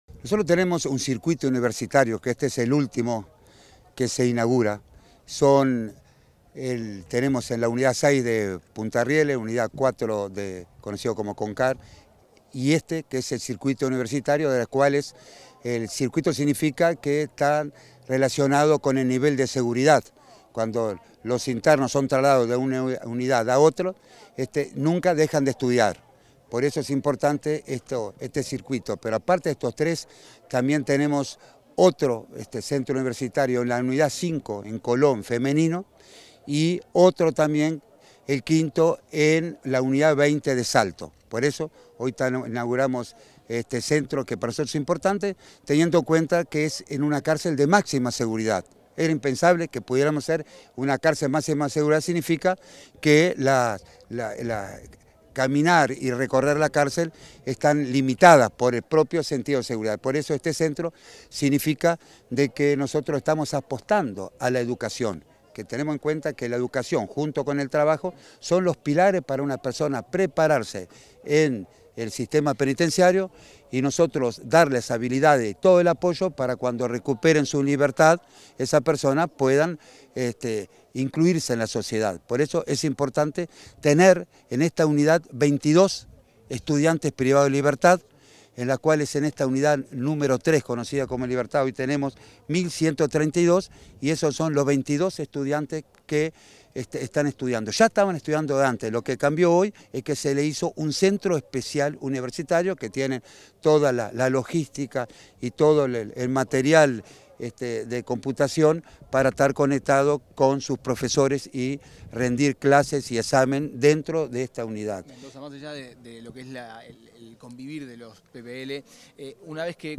Declaraciones del director del INR, Luis Mendoza 11/04/2024 Compartir Facebook Twitter Copiar enlace WhatsApp LinkedIn Tras la inauguración de un Centro Universitario en la Unidad 3, este 11 de abril, el director del Instituto Nacional de Rehabilitación (INR), Luis Mendoza, realizó declaraciones a la prensa.